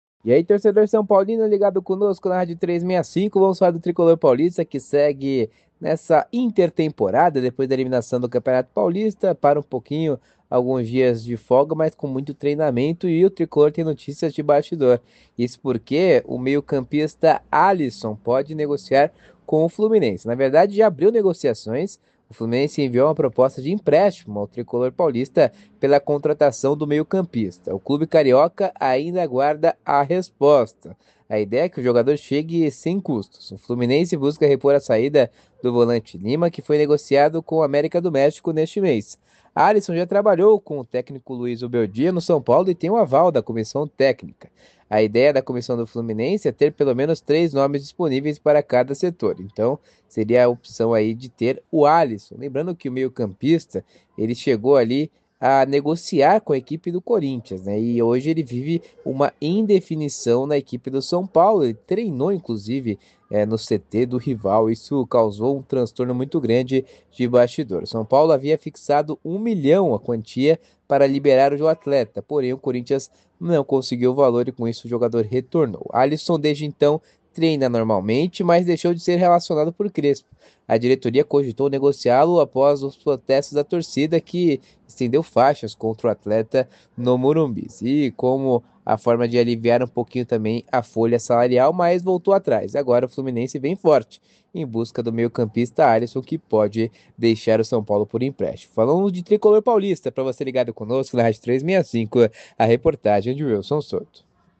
Boletin em áudio